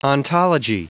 Prononciation audio / Fichier audio de ONTOLOGY en anglais
Prononciation du mot ontology en anglais (fichier audio)